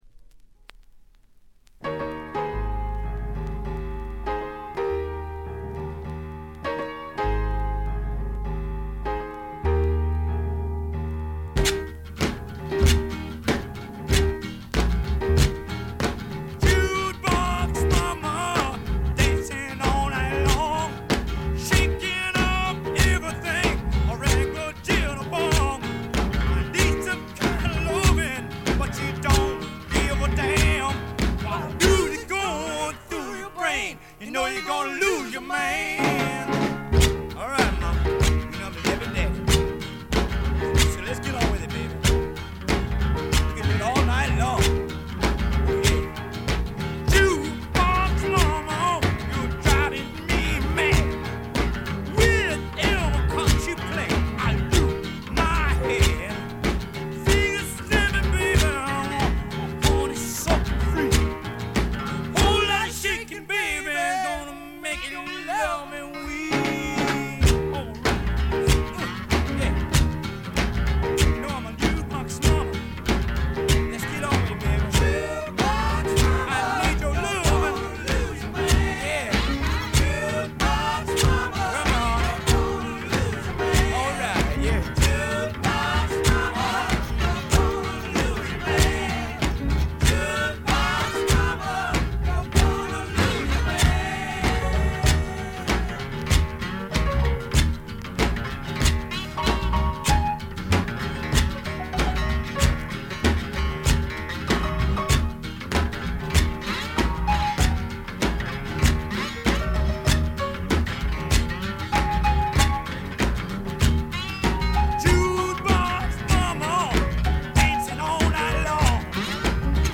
最小限のバック編成と搾り出すようなヴォーカルが織り成す、ねばつくような蒸し暑いサウンド。
試聴曲は現品からの取り込み音源です。